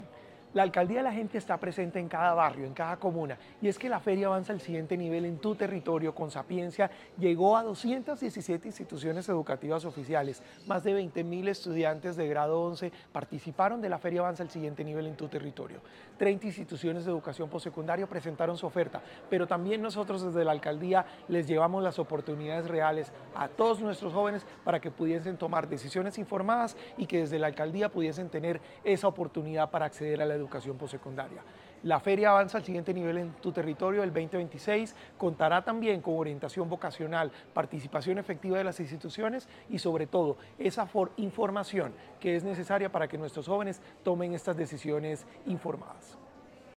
Declaraciones director general de Sapiencia, Salomón Cruz Zirene
Declaraciones-director-general-de-Sapiencia-Salomon-Cruz-Zirene.mp3